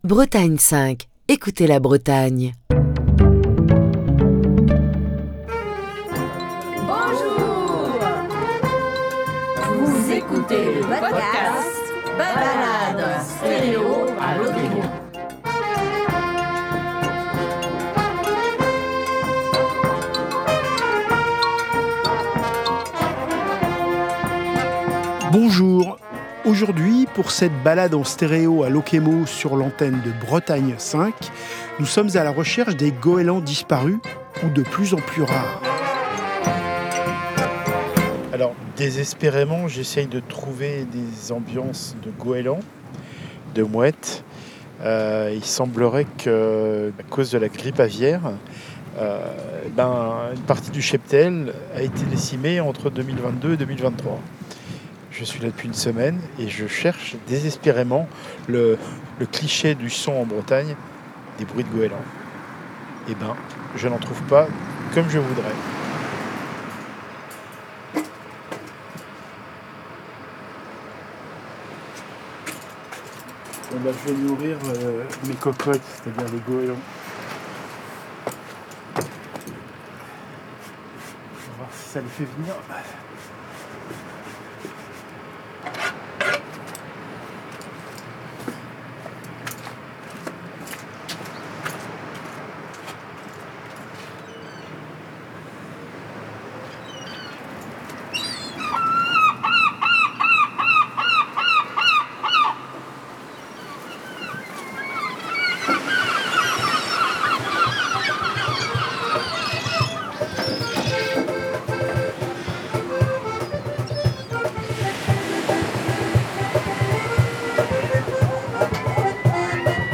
Balade en stéréo à Lokémo - 4ème épisode | Bretagne5